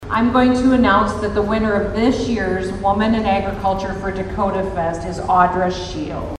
Governor Kristi Noem performed a special duty during Dakotafest on Thursday…..